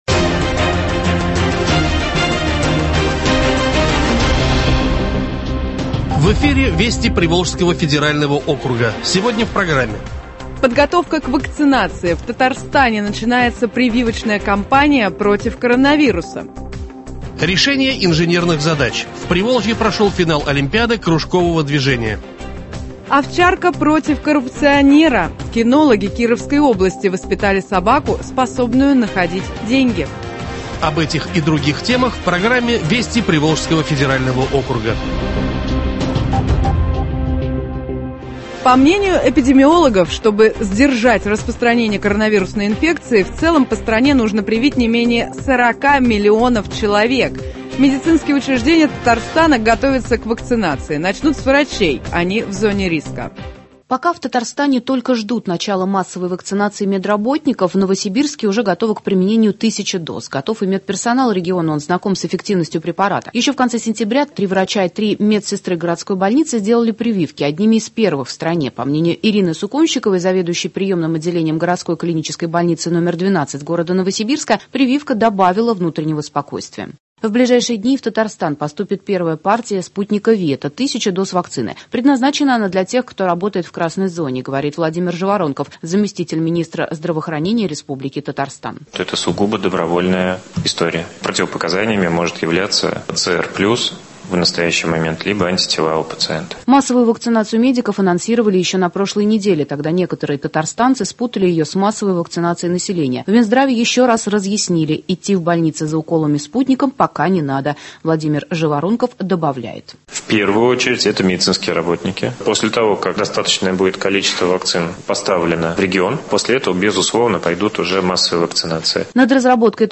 Радиообзор событий в регионах ПФО.